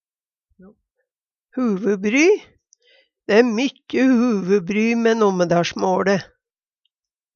DIALEKTORD PÅ NORMERT NORSK huvubry tankar, spekulering Eksempel på bruk Dæ e mykje huvubry mæ NommedaLsmåLe.l Hør på dette ordet Ordklasse: Uttrykk Kategori: Kropp, helse, slekt (mennesket) Attende til søk